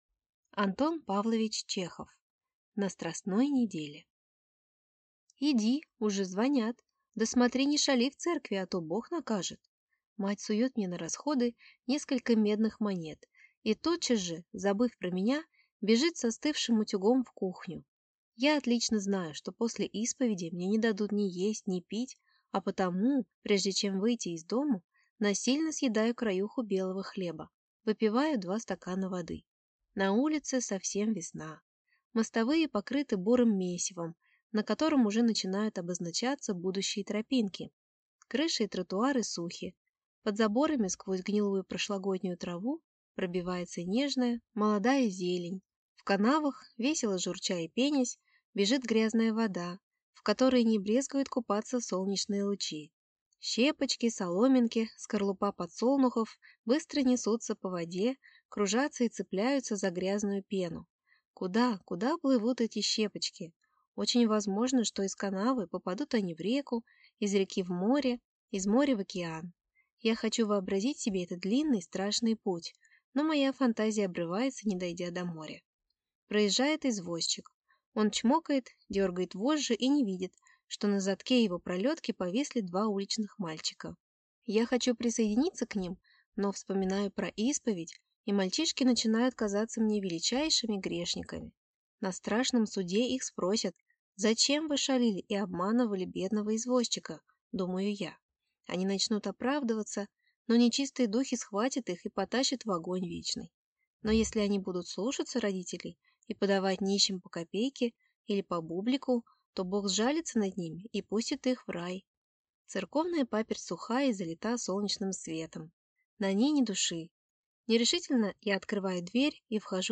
Аудиокнига На страстной неделе | Библиотека аудиокниг